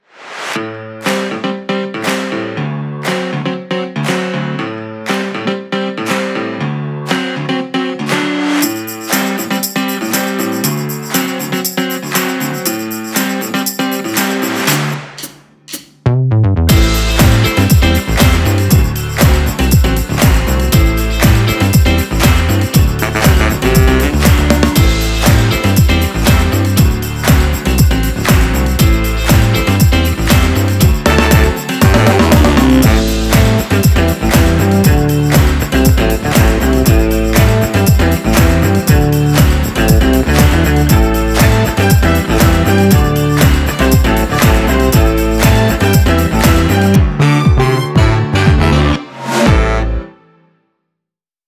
Genre: jazzfunk, funk.